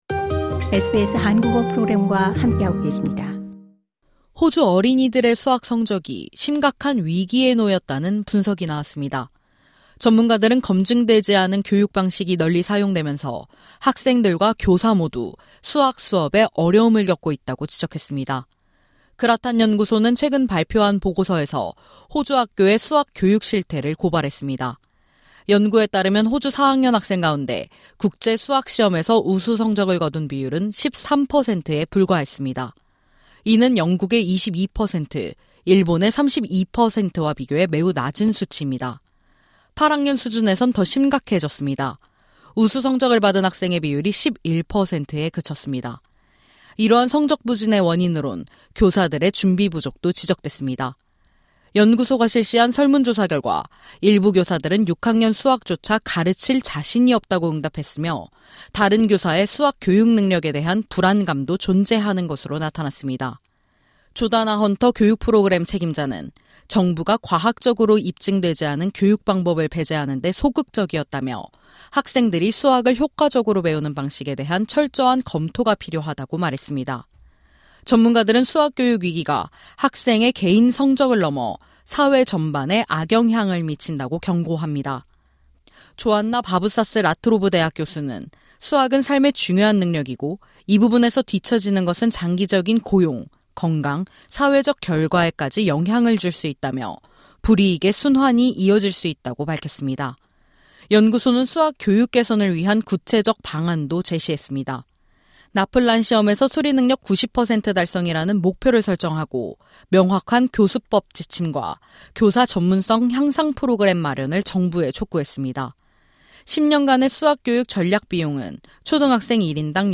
리드컴·스트라스필드 포함 SBS Korean 14:17 Korean 상단의 오디오를 재생하시면 뉴스를 들으실 수 있습니다.